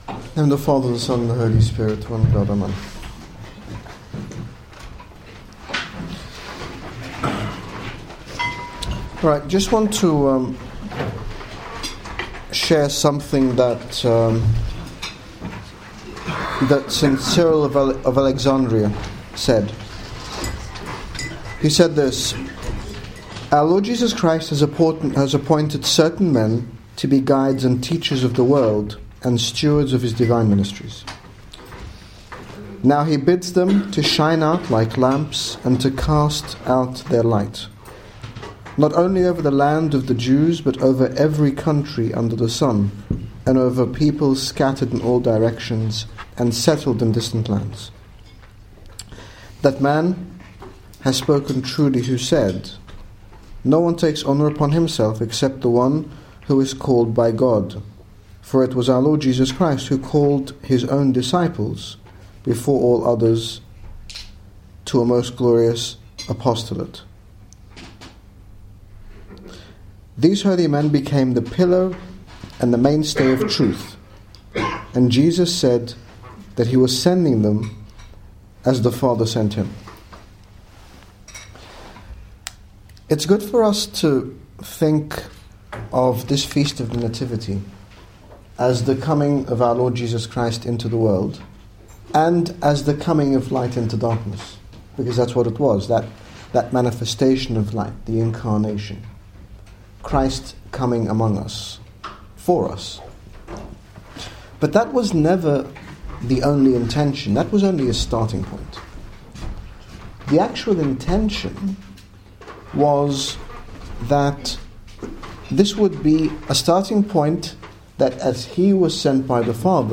In this short sermon His Grace Bishop Angaelos, General Bishop of the Coptic Orthodox Church in the United Kingdom, speaks about our mission and calling to bring light and hope into the world, relating our mission today to that of the disciples of our Lord Jesus Christ.
Accepting our mission in the world - 2014 Christmas Trip.mp3